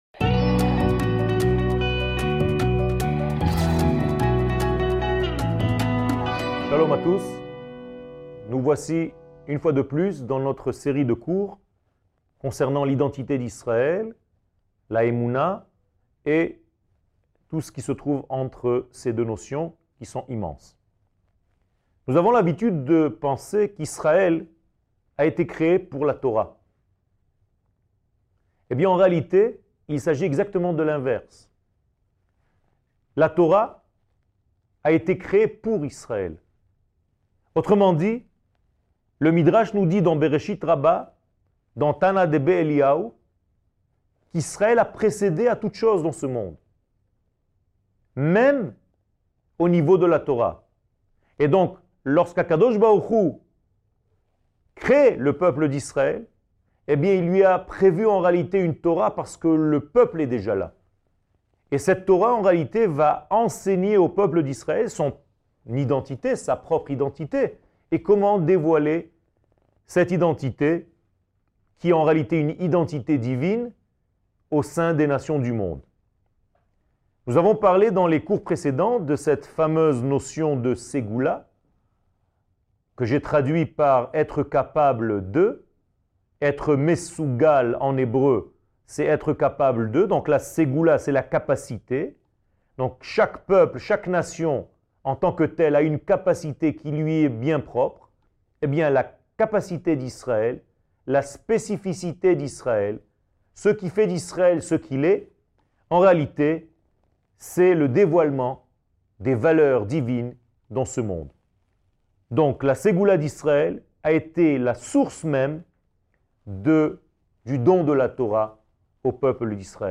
שיעורים, הרצאות, וידאו